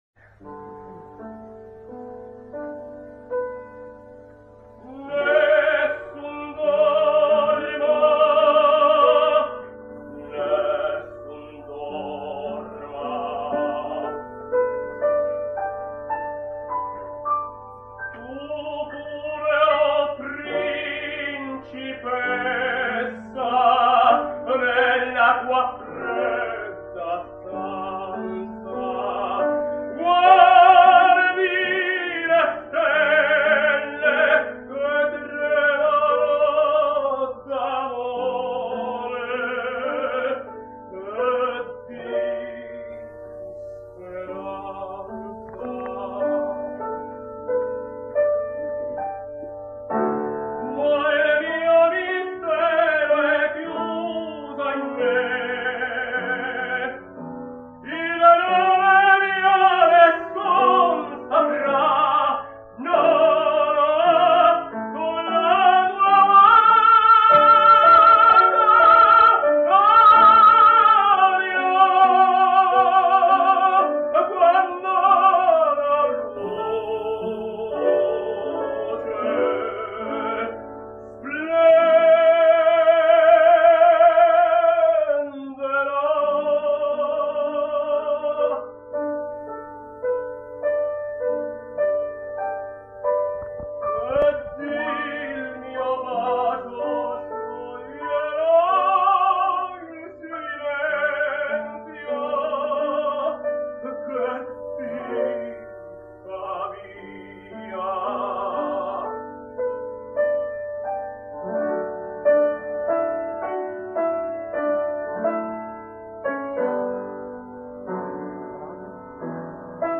Tenors singing Nessun dorma